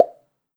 Add notification sound + muting system.
bubblepop.wav